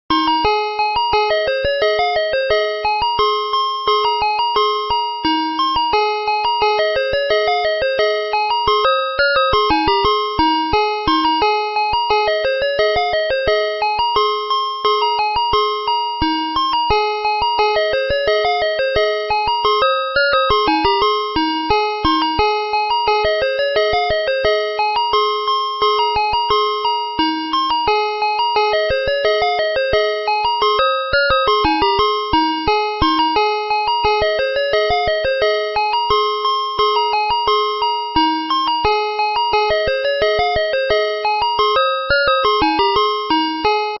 ice-cream-truck.mp3